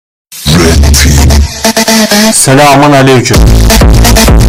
selamun-aleykum-remix-bass-boosted-audiotrimmer.mp3